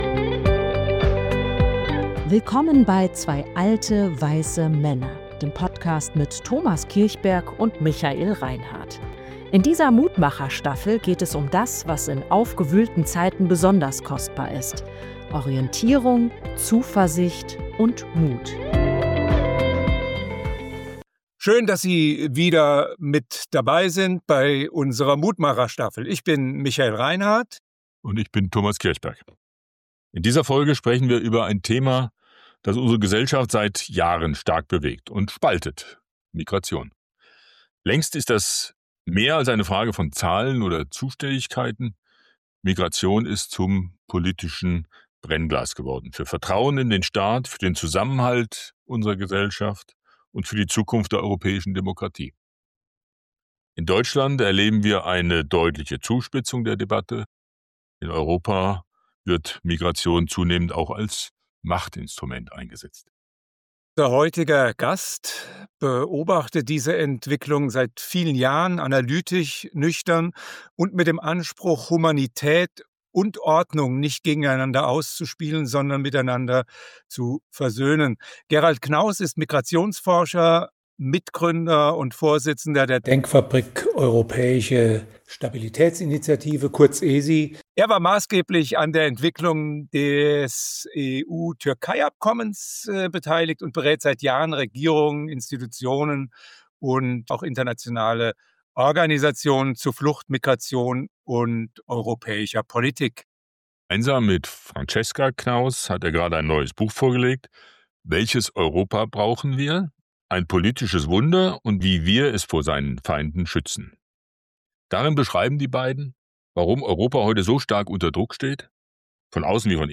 In dieser Folge der Mutmacher-Staffel sprechen wir mit Gerald Knaus, Migrationsforscher und Gründer der Europäischen Stabilitätsinitiative (ESI), über die Frage, wie Demokratie...